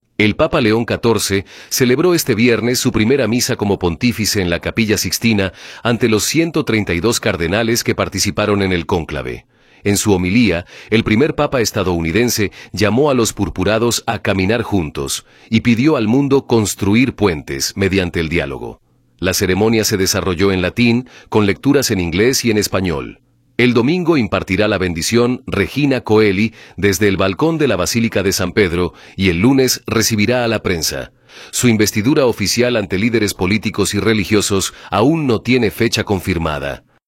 El Papa León XIV celebró este viernes su primera misa como pontífice en la Capilla Sixtina ante los 132 cardenales que participaron en el cónclave. En su homilía, el primer Papa estadounidense llamó a los purpurados a “caminar juntos” y pidió al mundo “construir puentes” mediante el diálogo.